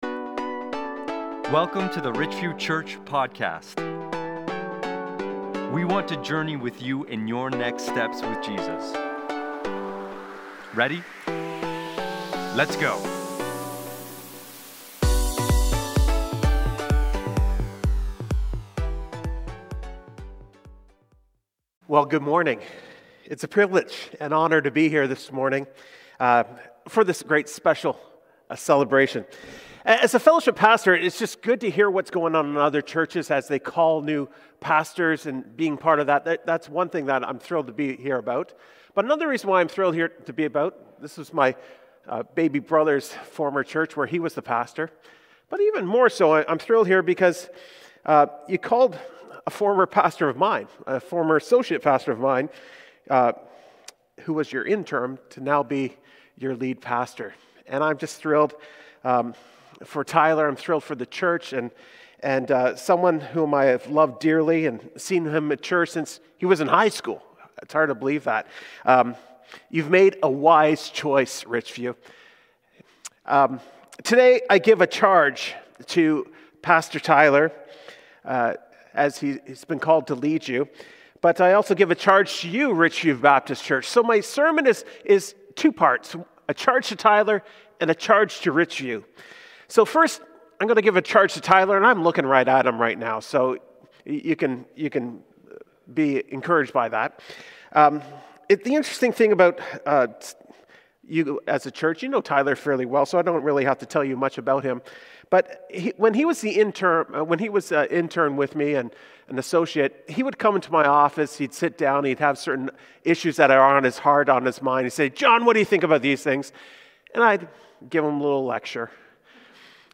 Induction Service